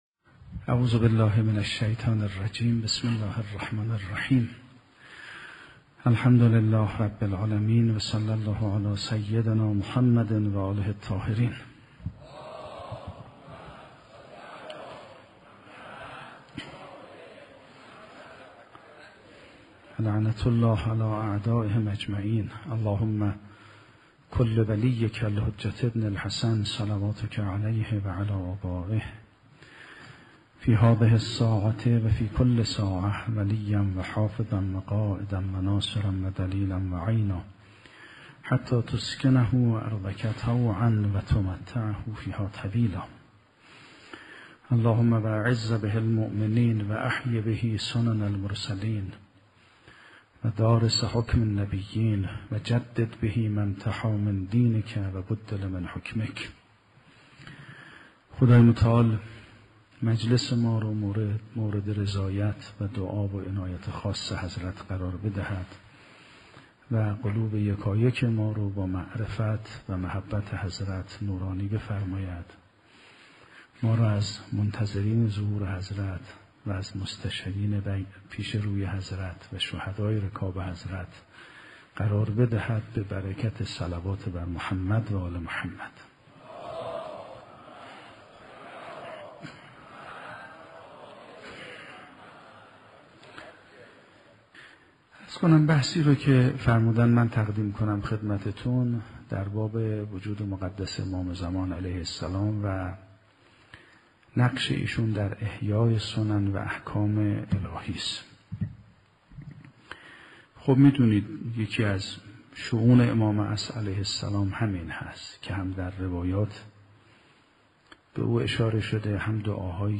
صوت سخنرانی مذهبی و اخلاقی